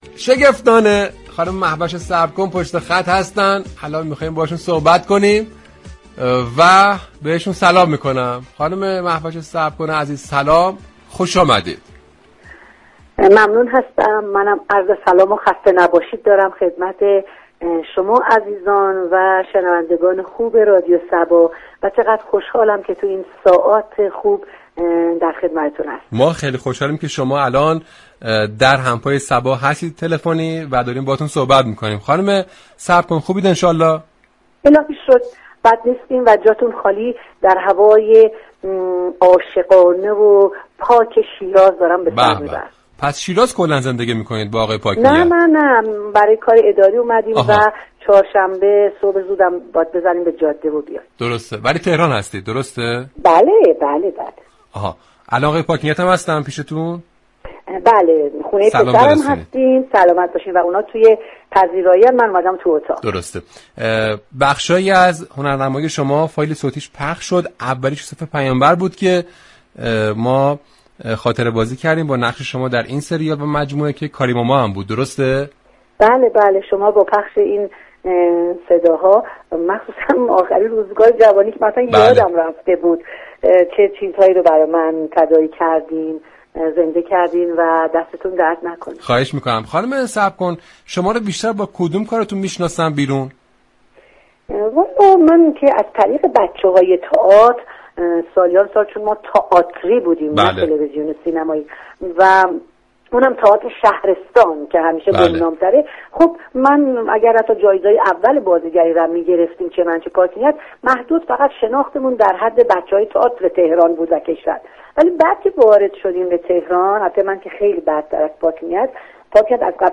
مهوش صبركن در گفتگو برنامه «همپای صبا» داستان وابستگی یك خانواده هنرمند به رادیو صبا را بازگو كرد.
این گفتگو كه با پخش آیتم كوتاهی از مجموعه كارهای تلویزیونی وی شروع شد، خاطرات گذشته را برای مهوش صبركن تداعی كرد به ویژه سریال پس از باران و یوسف پیامبر، وی درباره این سریال ها گفت: من كارم را با تئاتر شهرستان شروع كردم و مردم مهوش صبر كن را با بازی نقش خانم بزرگ در سریال پس ازباران شناختند.